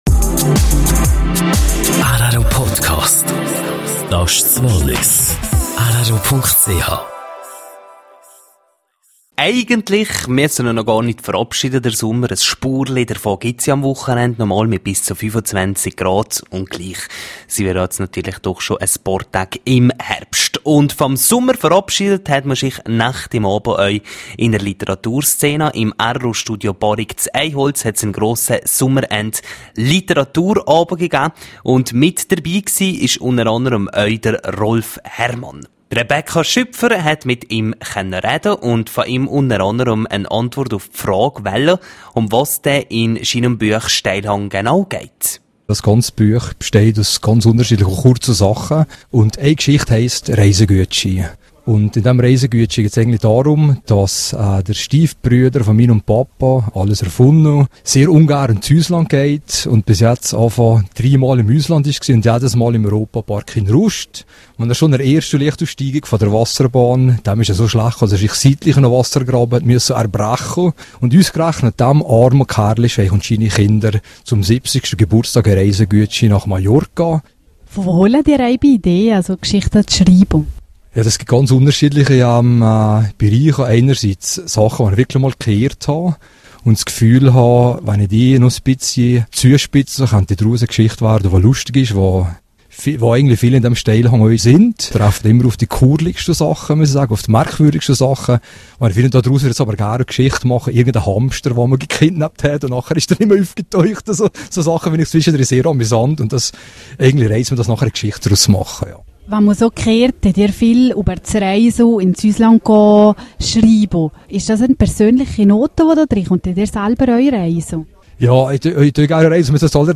Sommerend-Literatur aus dem rro-Studio Barrique in Eyholz.